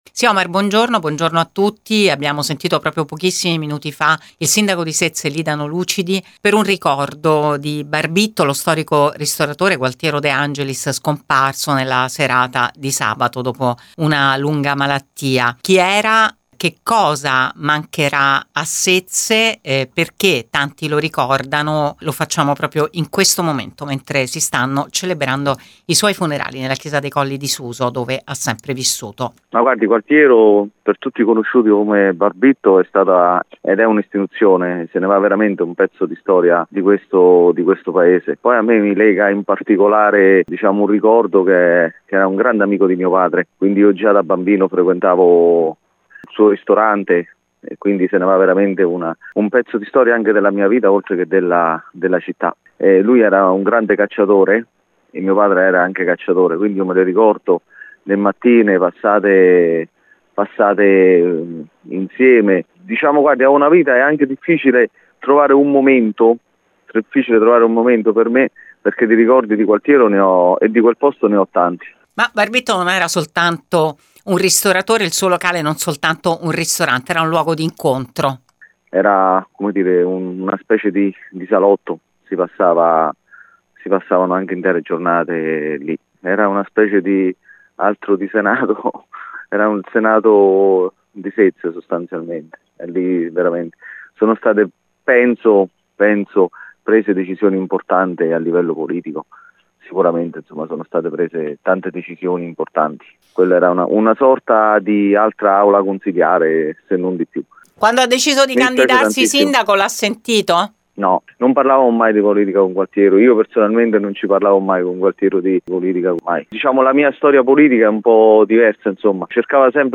L’amore per la caccia, per le escursioni in montagna a caccia di funghi e tartufi, i grandi raduni al tramonto e tanti altri ricordi di famiglia, nel racconto del primo cittadino che ha accettato il nostro invito su Radio Immagine.